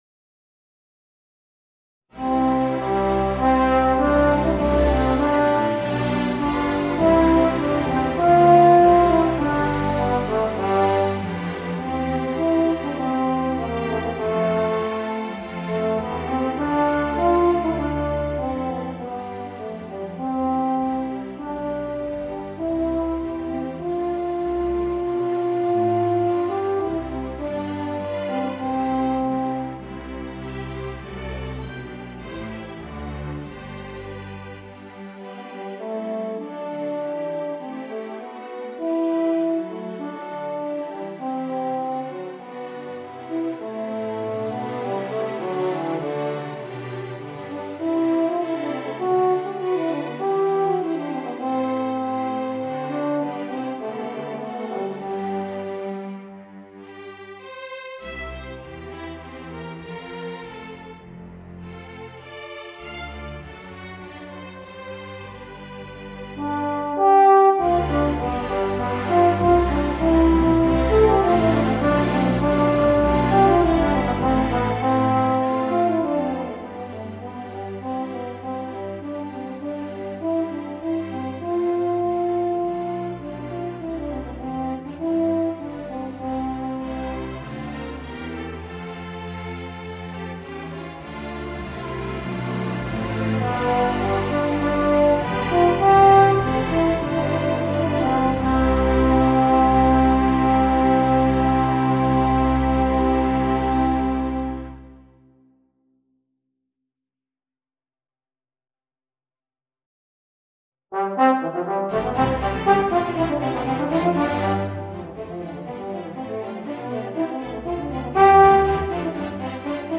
Voicing: Soprano Recorder and String Orchestra